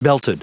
Prononciation du mot belted en anglais (fichier audio)
Prononciation du mot : belted